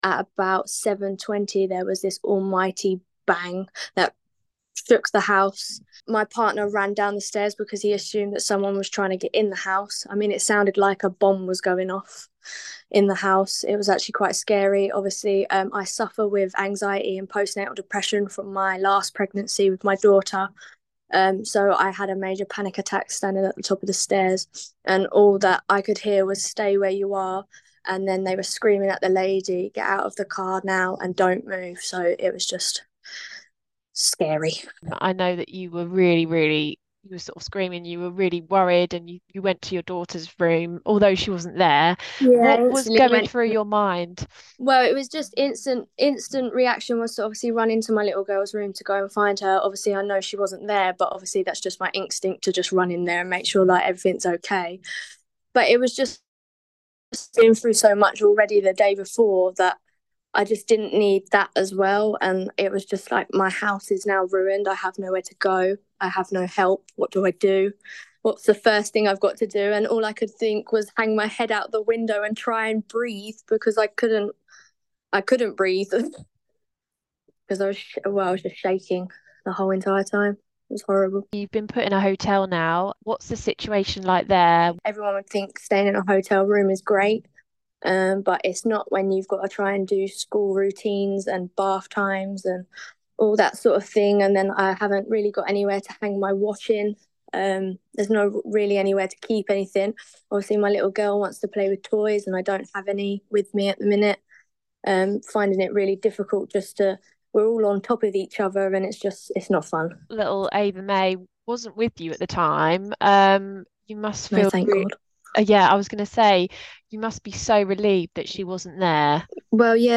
Listen: a Maidstone mum has described the moment a car crashed into the front of her house - 10/01/2024